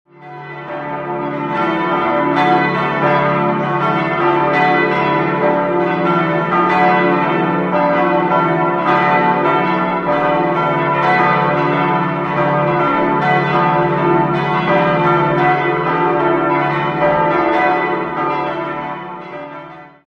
Das Innere ist mit spätbarocken Altären ausgestattet. 5-stimmiges ausgefülltes Salve-Regina-Geläute: d'-e'-fis'-a'-h' Die Glocken wurden 1949 von Petit&Edelbrock in Gescher gegossen.